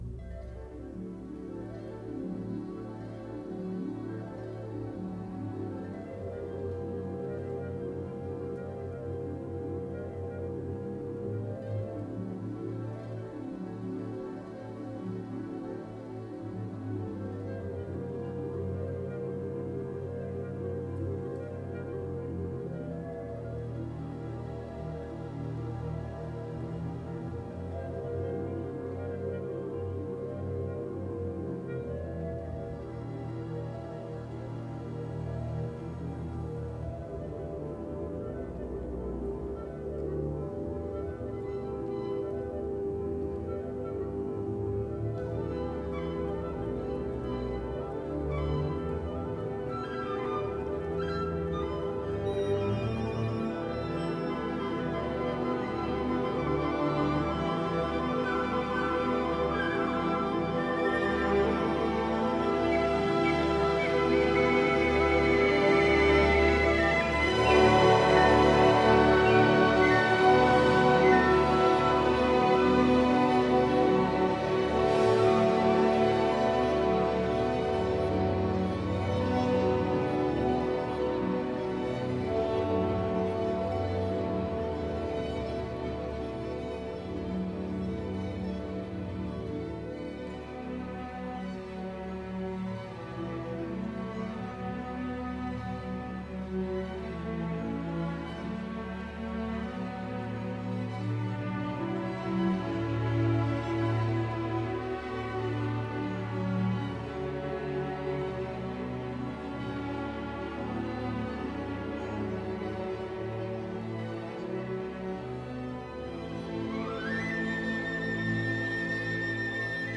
by Boston Symphony Orchestra, Tanglewood Festival Chorus; Charles Munch, Robert Shaw | Ravel: Daphnis et Chloé